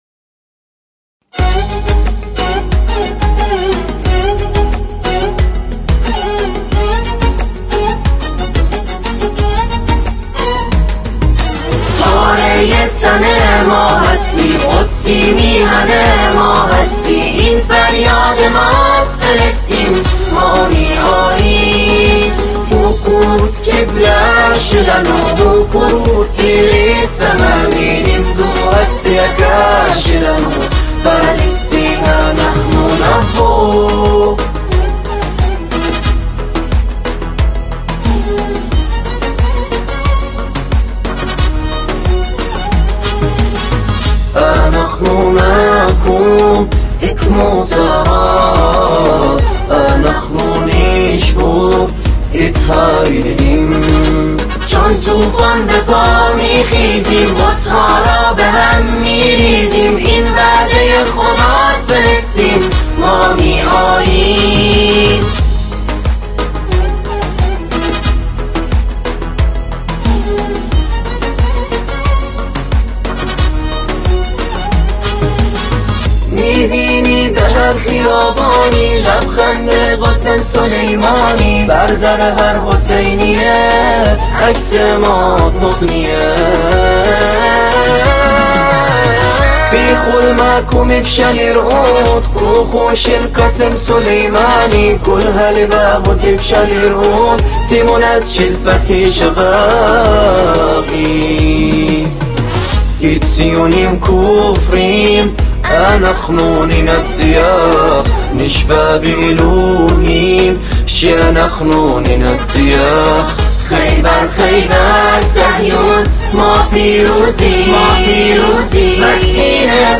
سرودهای ویژه مقاومت فلسطین
سرود